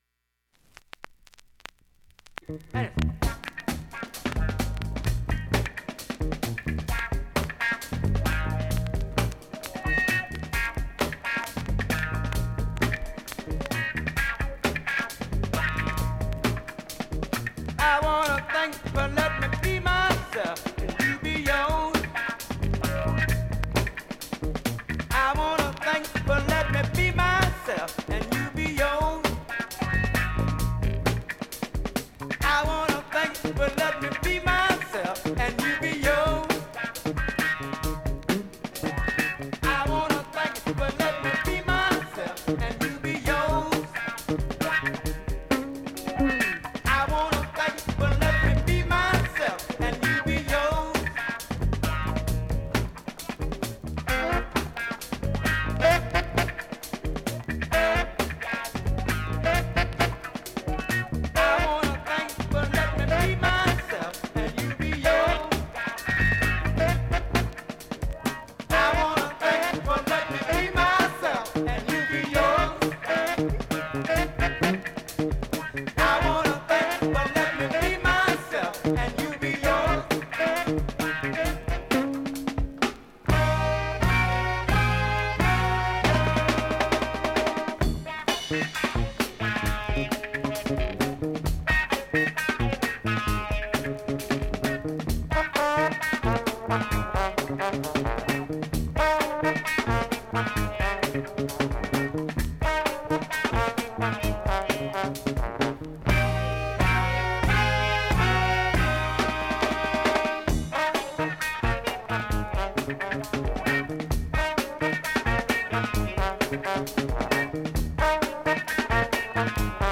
現物の試聴（両面すべて録音時間９分５５秒）できます。
中盤のタイトなブレイクがB-Boyにも人気